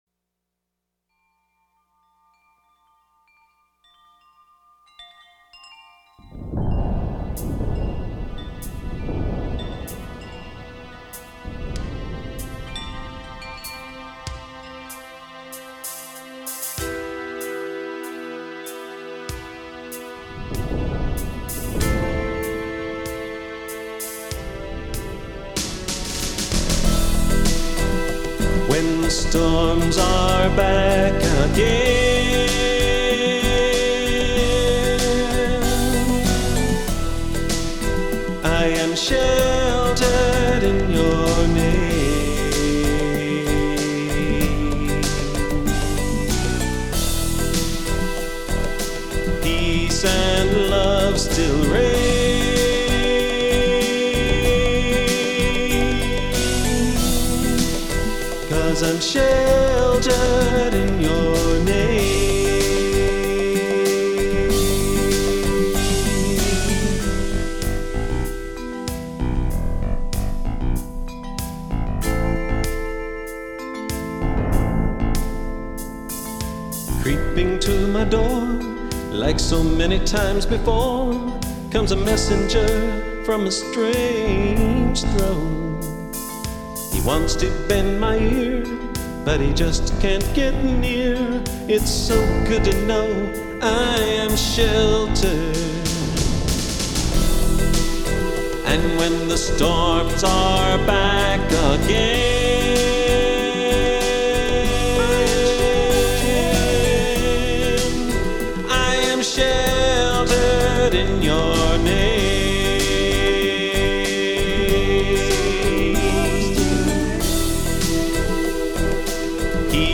This was the first album that we recorded in our own studio.